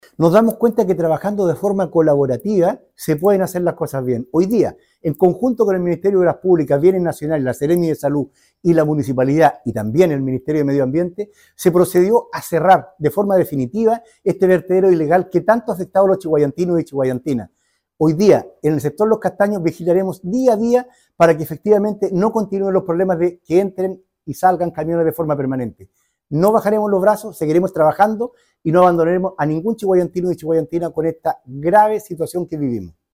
El alcalde de Chiguayante, Jorge Lozano, destacó el cierre y llamó “a la comunidad a respetar la ley y no avalar este tipo de negocios clandestinos, además de recordar la importancia de cuidar a nuestro río Biobío”.